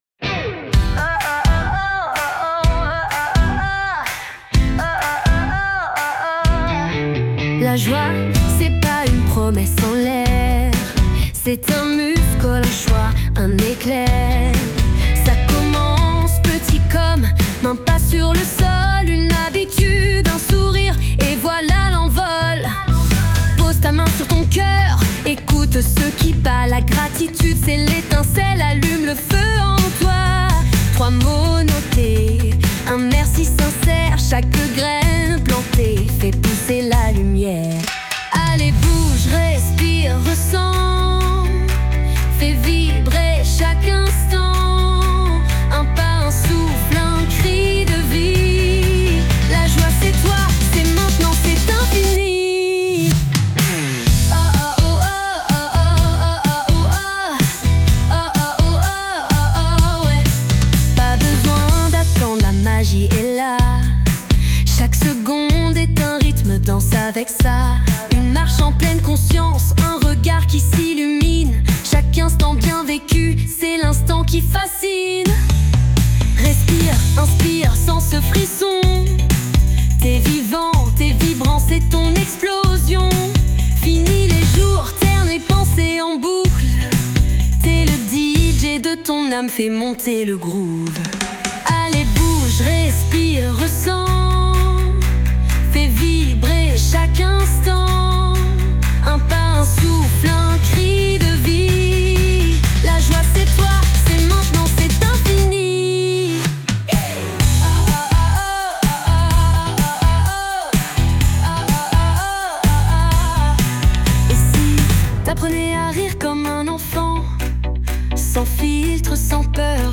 Et comme à notre habitude, juste avant, écoutez cette chanson créée pour vous donner la pêche (et rajouter de la joie à votre journée) :